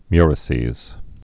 (myrĭ-sēz)